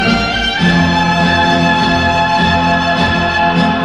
El final de la 3ª frase son tres notas: